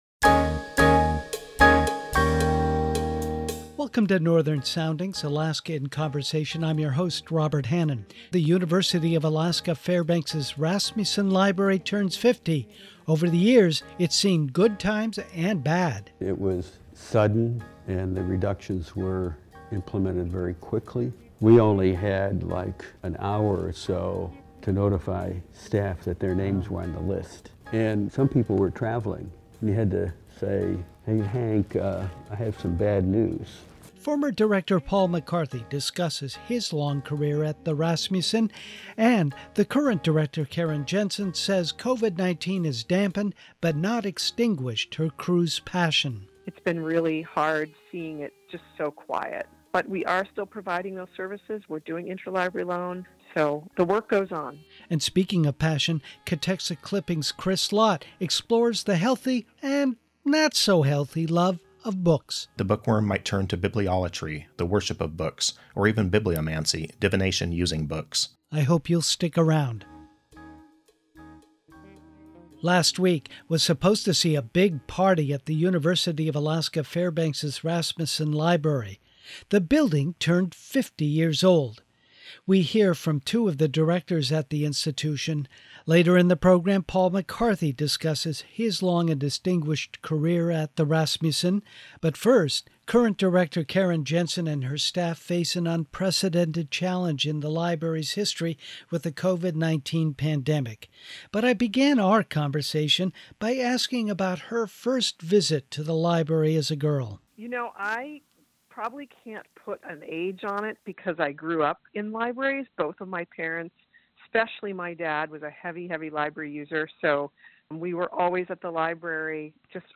First, we begin with a conversation via Skype